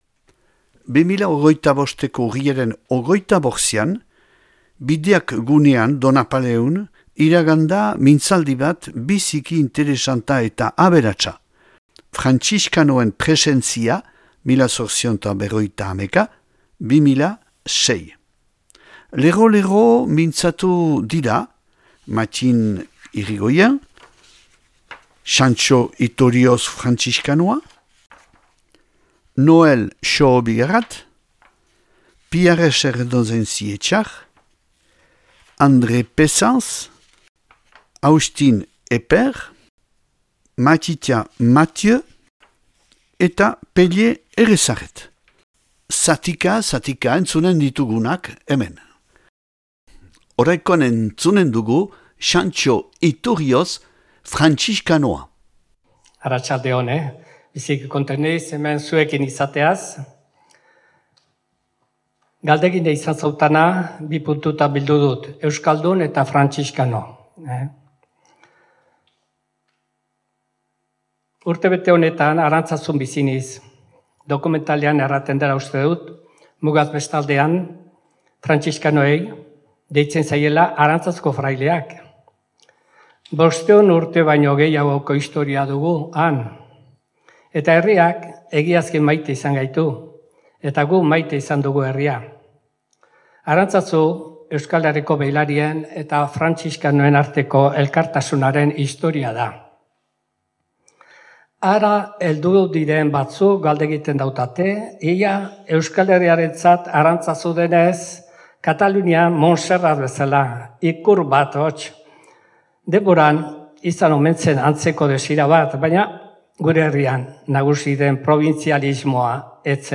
2025ko Urriaren 25ean, Bideak gunean Donapaleun, iragan da mintzaldi bat biziki interesanta eta aberatsa : Frantziskanoen presentzia 1851-2006.